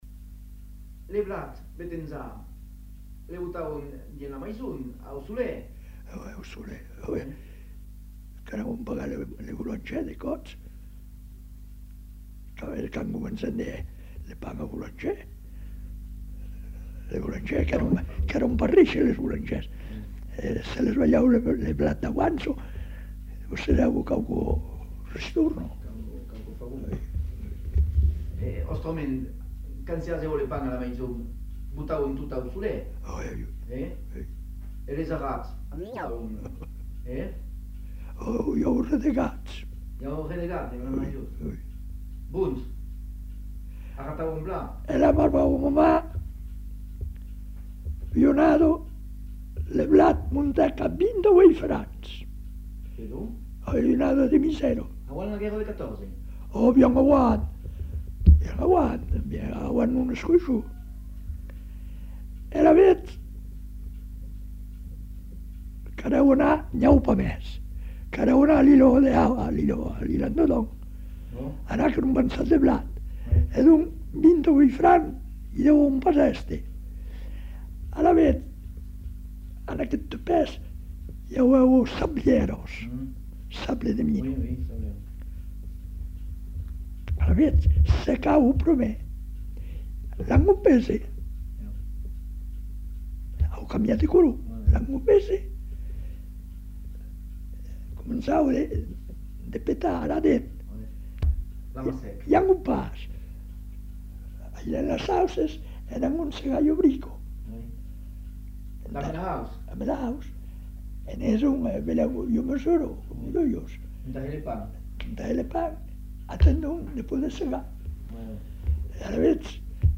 Aire culturelle : Savès
Genre : témoignage thématique